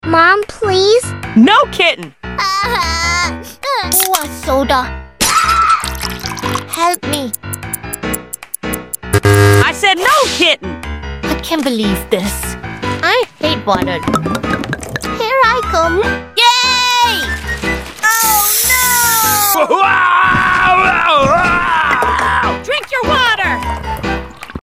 Kitten has a soda suprise sound effects free download